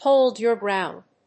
アクセントHóld your gróund!